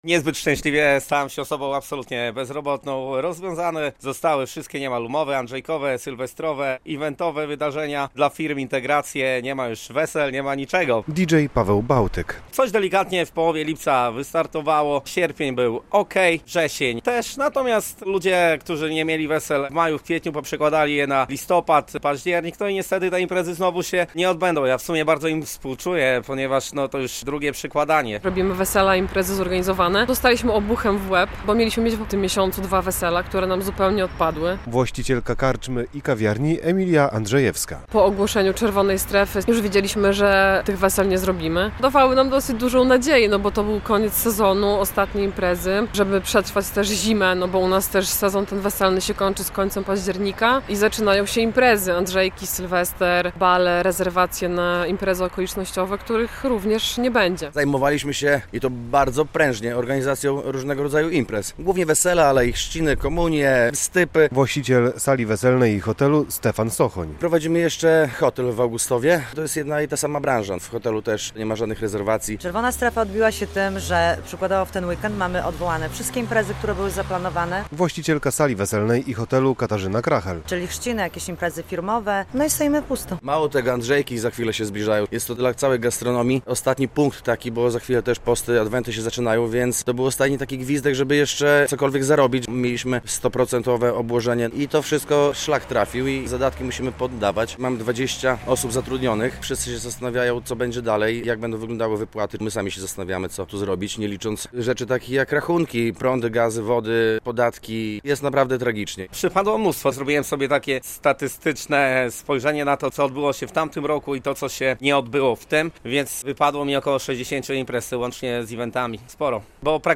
Przedstawiciele branży eventowej i weselnej nie mogą prowadzić działalności - relacja